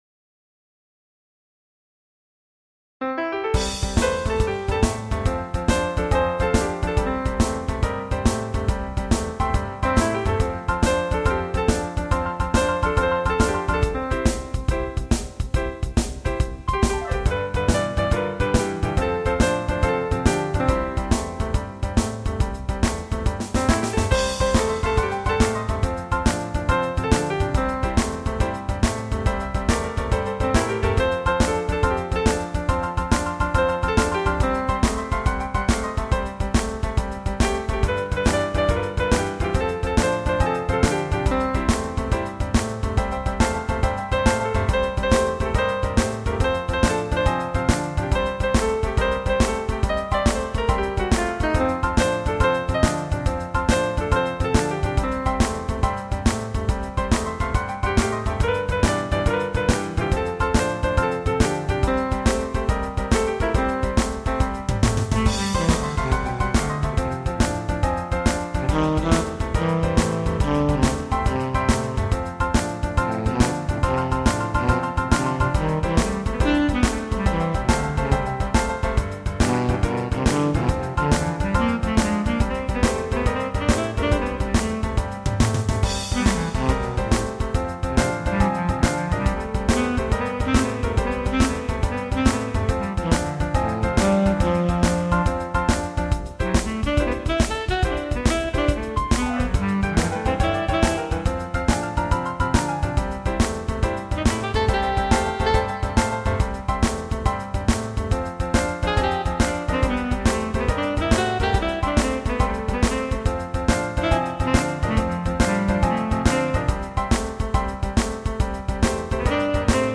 Blues2.mp3